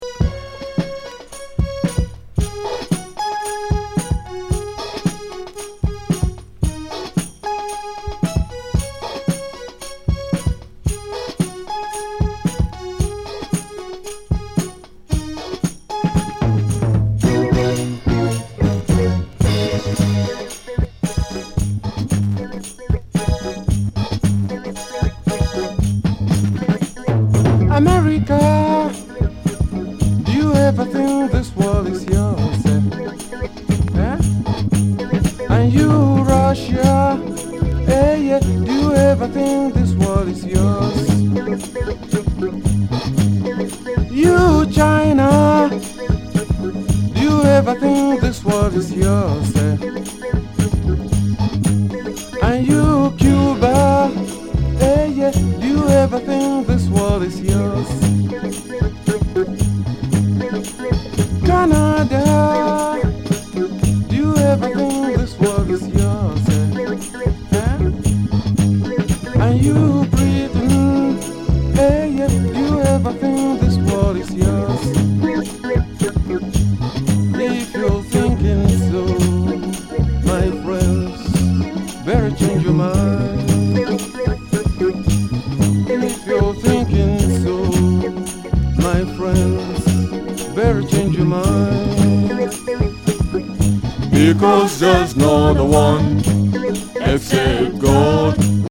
チープ電子音とナード・ボーカルとファンクネスの絶妙バランス・アフロ・ディスコ